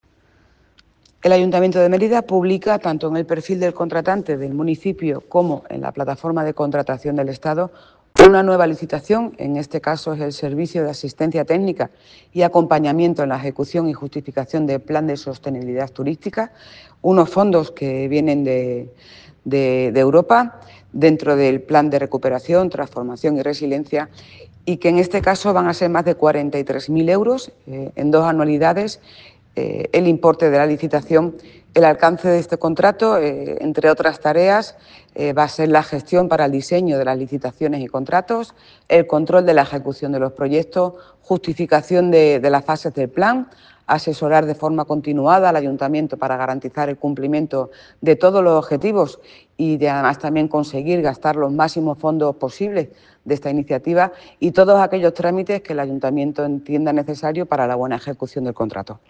Para más información sobre la subasta y el plan de sostenibilidad turística, se puede escuchar el audio de la declaración de Silvia Fernández en el siguiente enlace:
Audio de Silvia Fernández sobre la subasta del plan de sostenibilidad turística